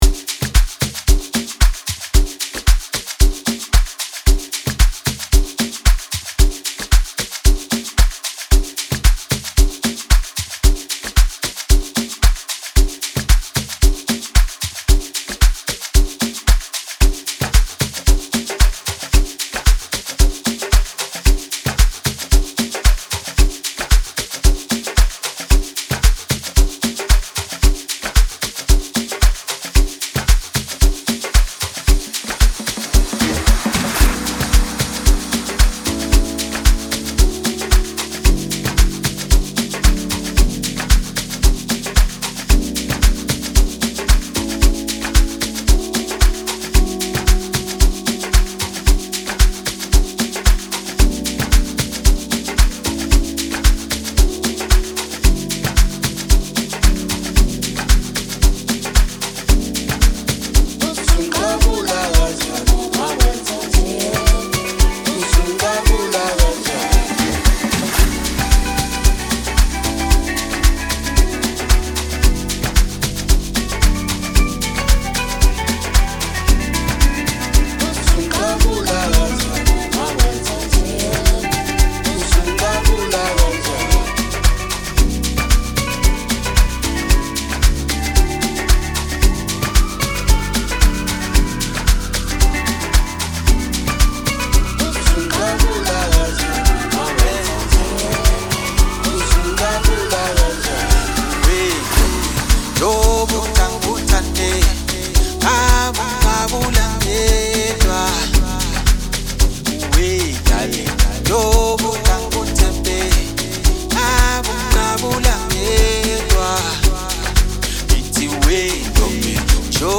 soulful and enchanting track
known for his smooth and captivating voice.